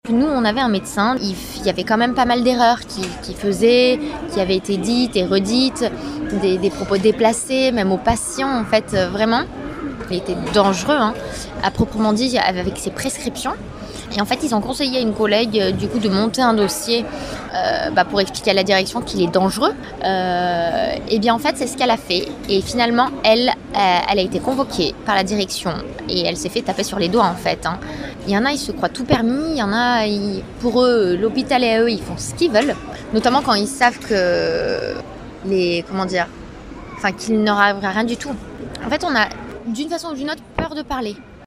Elle a souhaité rester anonmye.
correction-temoignage-infirmiere-monte-42774.mp3